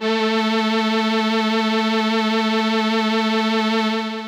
55bd-syn10-a3.aif